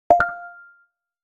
Notification-02.m4a